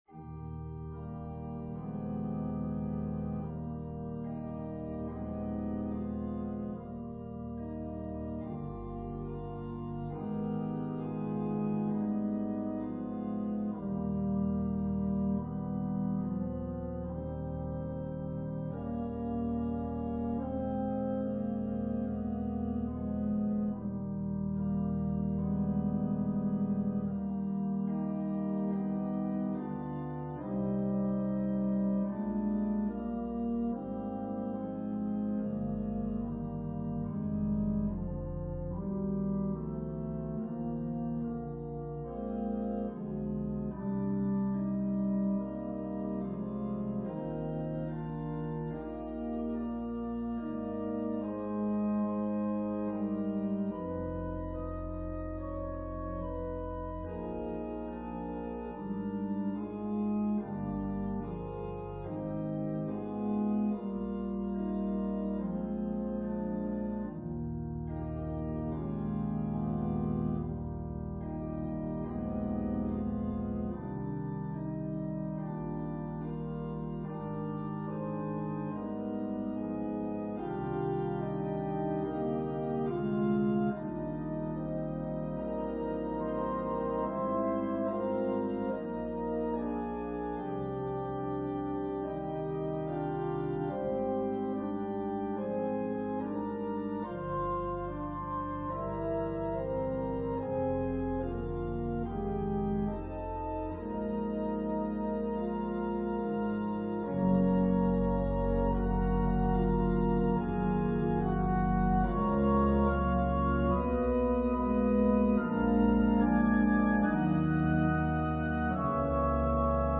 Voicing/Instrumentation: Organ/Organ Accompaniment
An organist who likes to arrange music for organ.